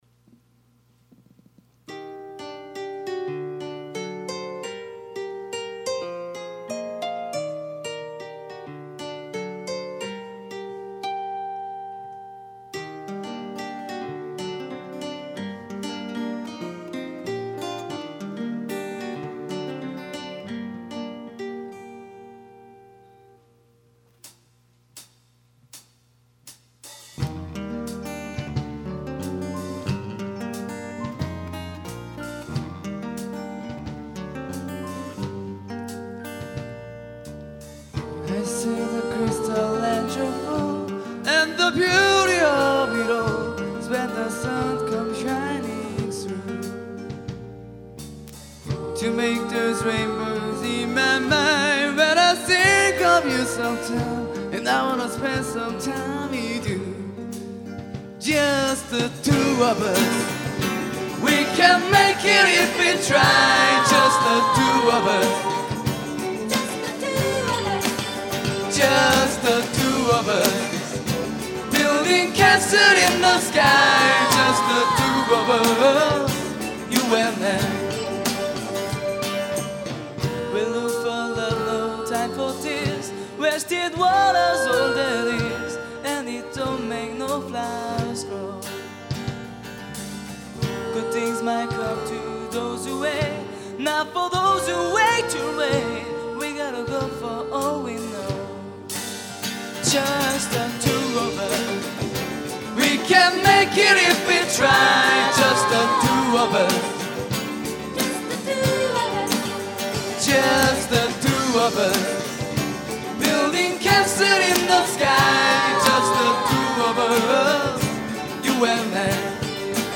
2005년 신입생 환영공연
홍익대학교 신축강당
어쿠스틱기타
베이스
일렉트릭기타
드럼
신디사이저